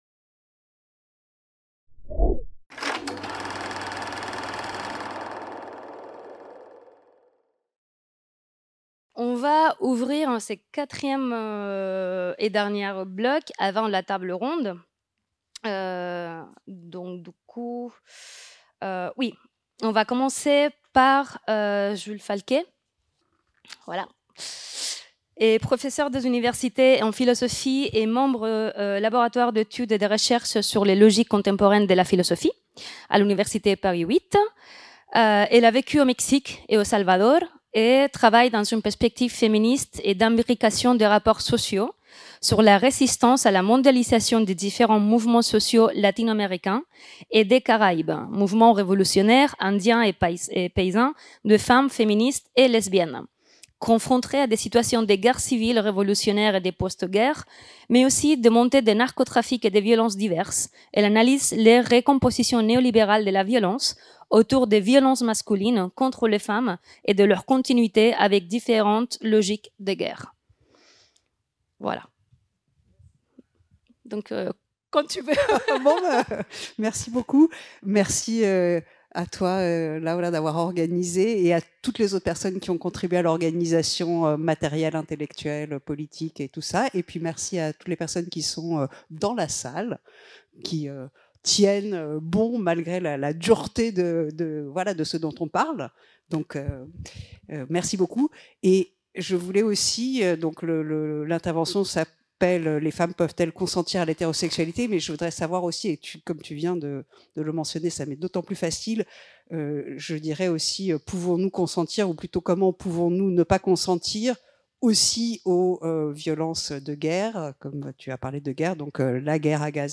Quatrième session de la journée Sexe et démocratie. De l'enjeu du consentement, qui s'est tenue le 30 mai 2024 dans le Hall de la FMSH